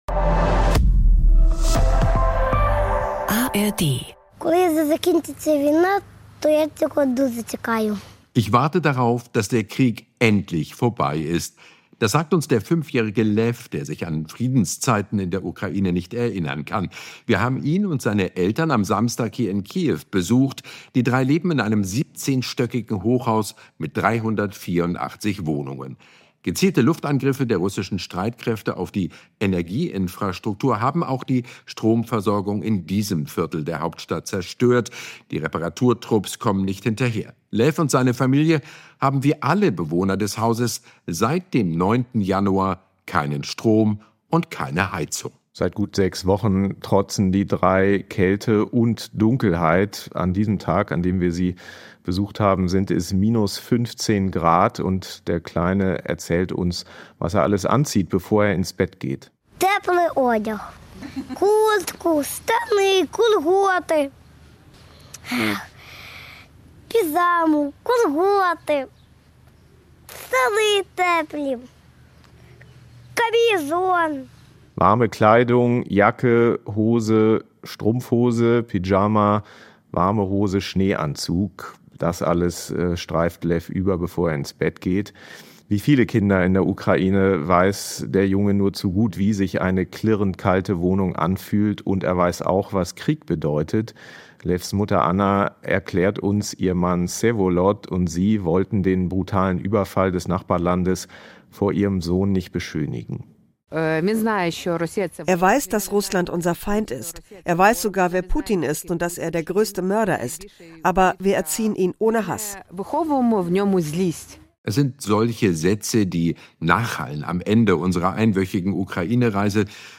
Beschreibung vor 1 Monat Vier Jahre nach Beginn der russischen Vollinvasion senden wir eine besondere Folge von Streitkräfte und Strategien - produziert in Kiew.
Die Hosts erleben an einem Tag junge Menschen bei einer Techno-Nacht in Kiew, die sich zwischen Luftalarm und Lebenswillen ein paar Stunden Normalität gönnen. An einem anderen Tag besuchen sie eine geheime Drohnenfabrik.